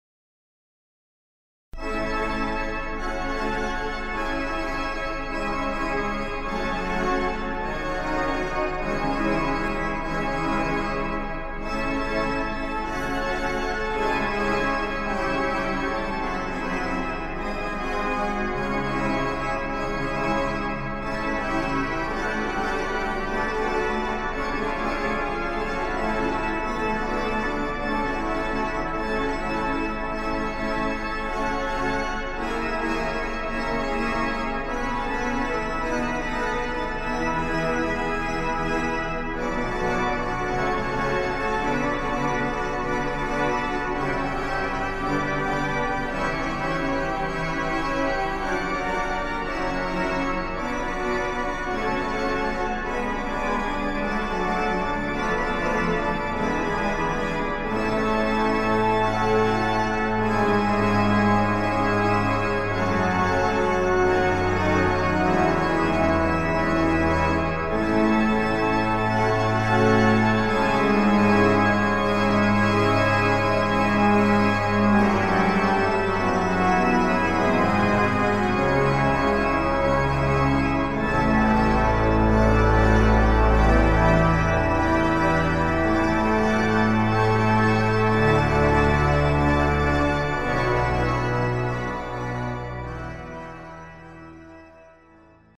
Basilica del Sacro Cuore di Cristo Re
Concerto organistico in onore dei nuovi Santi Papa Giovanni XXIII e Papa Giovanni Paolo II
Toccata in la maggiore